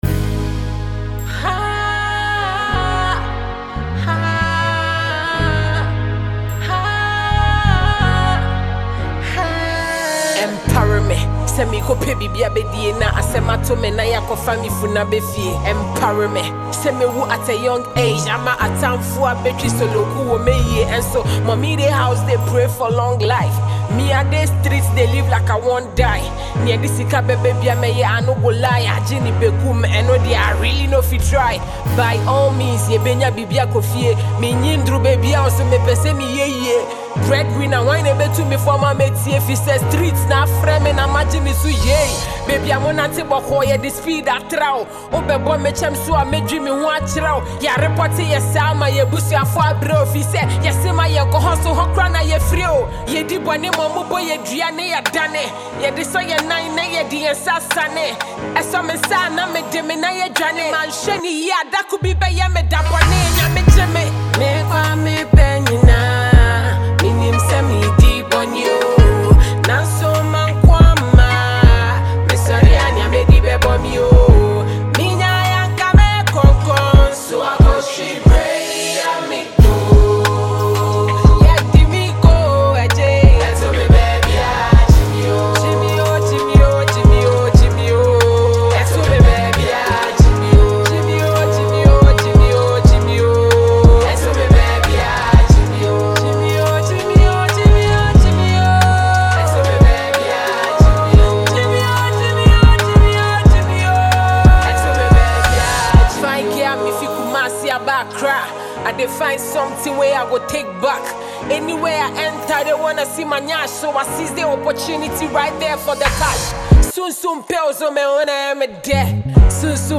Ghanaian female rapper